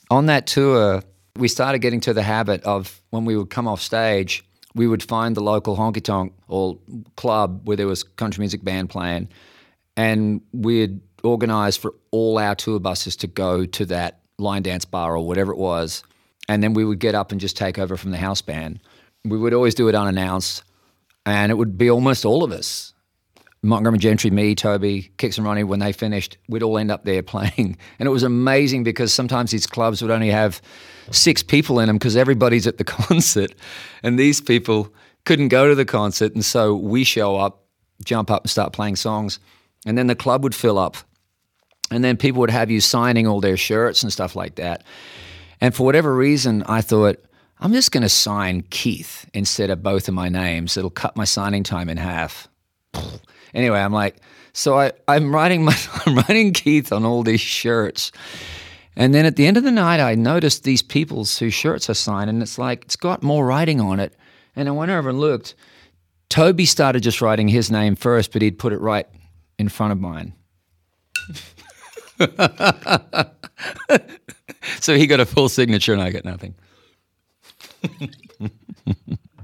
Keith Urban recalls a funny story about Toby Keith when he went on tour with him on Brooks & Dunn's Neon Circus Tour.